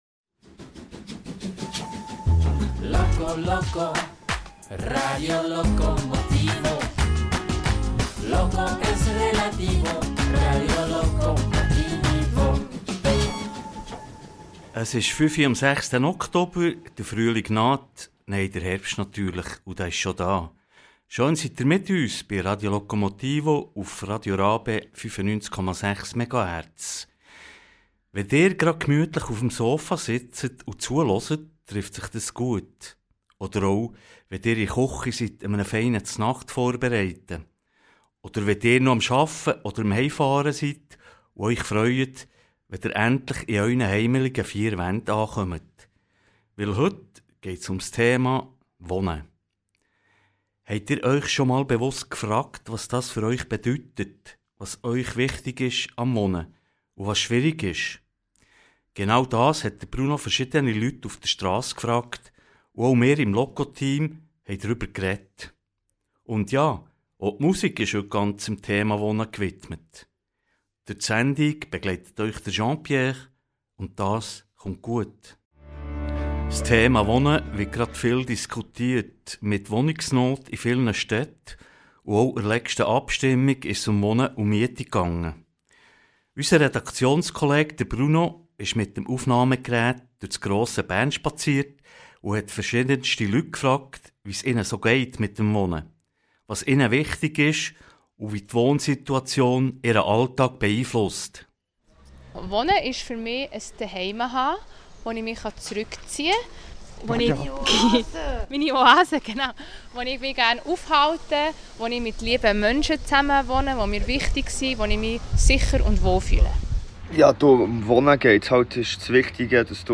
Er wanderte durch die Strassen von Bern und befragte diverse Menschen übers Wohnen. Und auch wir im Radio loco-motivo-Team haben uns darüber unterhalten.